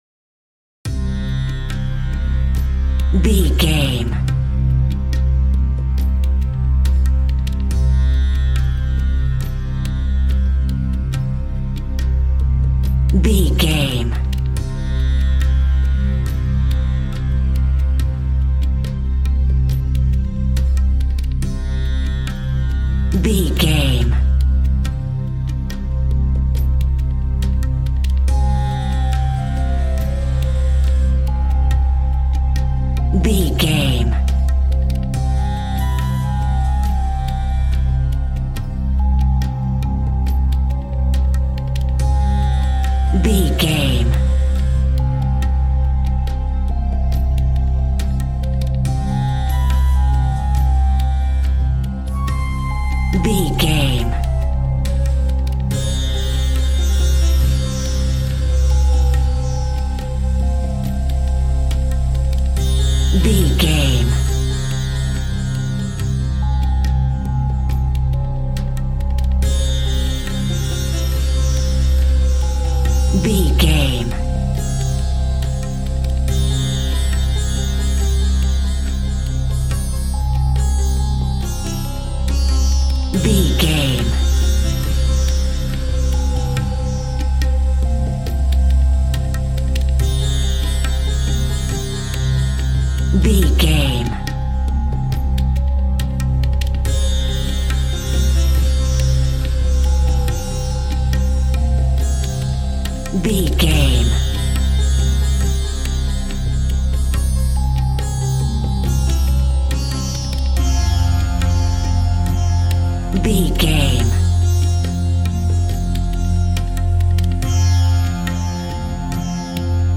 Ionian/Major
Slow
light
relaxed
tranquil
synthesiser
drum machine